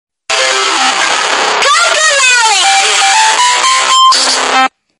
The Loudest Cocomelon Sound Of Sound Effects Free Download